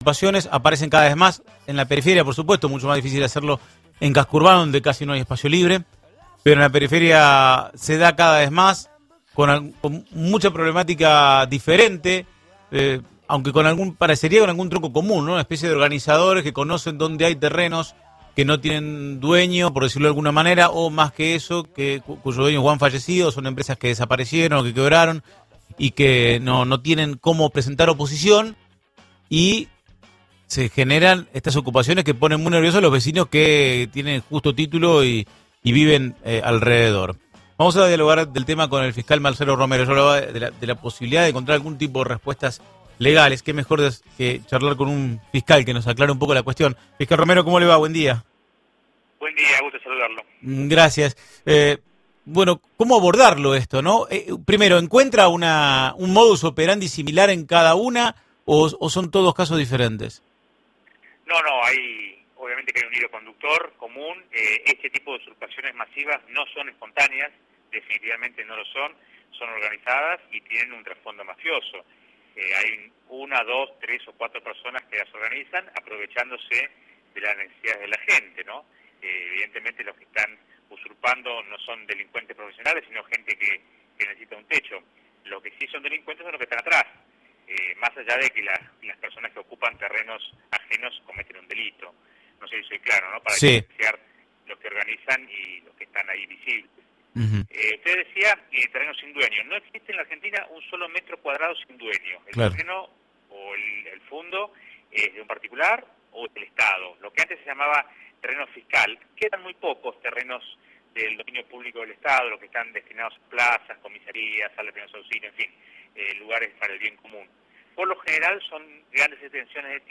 Esta mañana en diálogo con LA REDONDA 100.3, el fiscal Marcelo Romero se refirió a las tomas de terrenos que se vienen produciendo en la región.